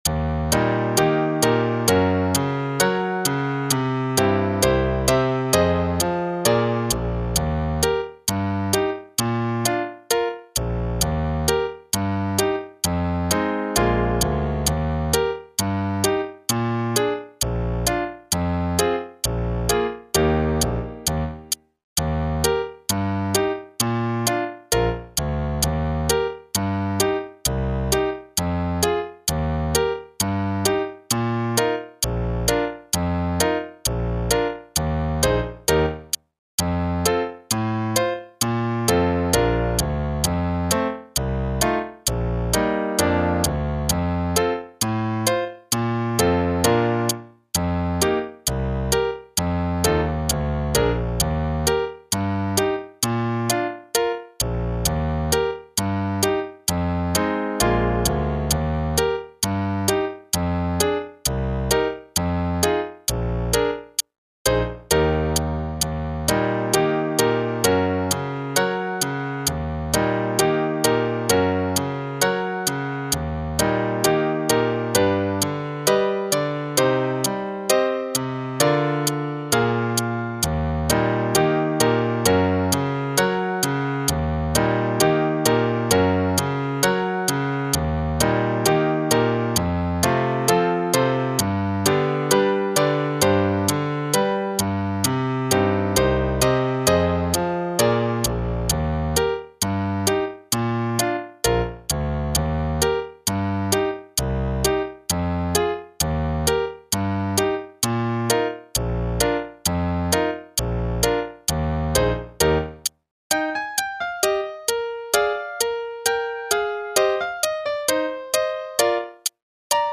(with click, qn=132)